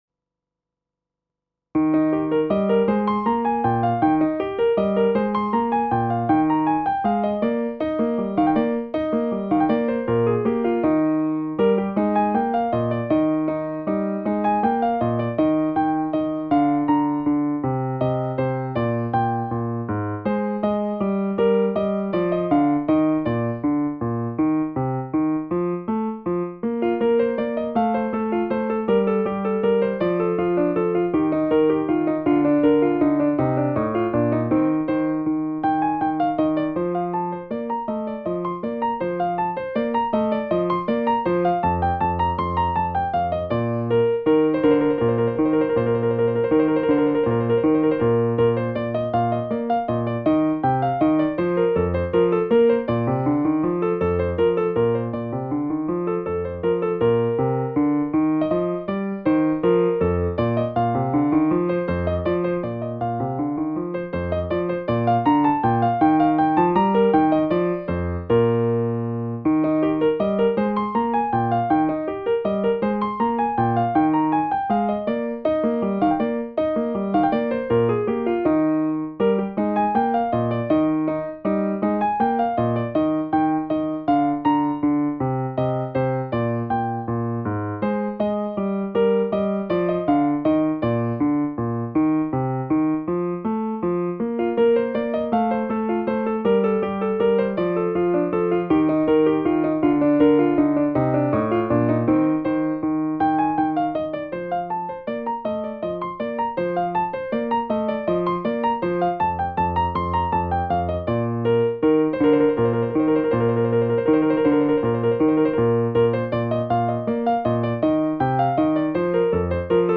Includes access to audio of the piano accompaniment.